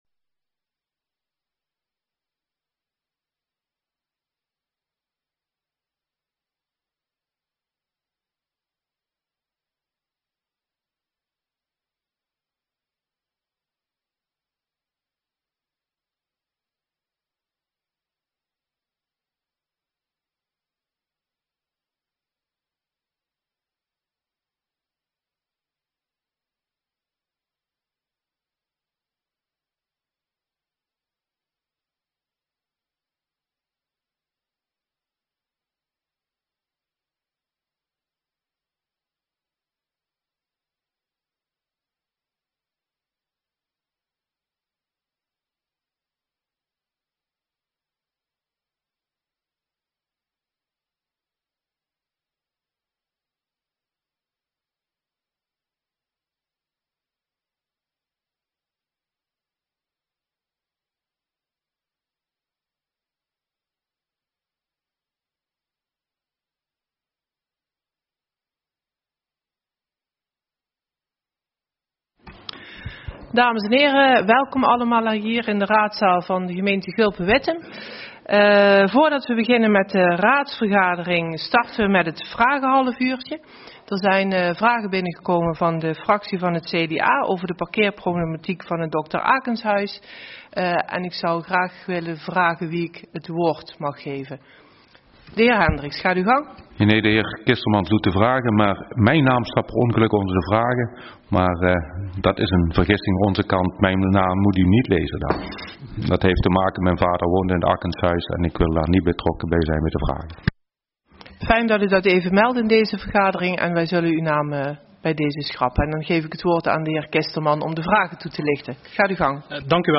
Locatie Raadzaal